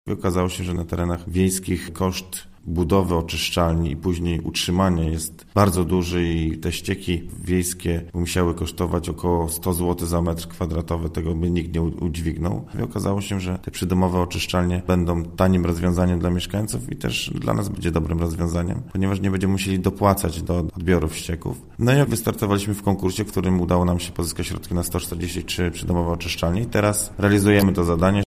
– Do takiego rozwiązania przekonywaliśmy mieszkańców niektórych miejscowości zwłaszcza, że otrzymamy dofinansowanie do tej inwestycji, która potrwa znaczni krócej, niż budowa typowej sieci – tłumaczy Paweł Mierzwiak, burmistrz Nowogrodu Bobrzańskiego.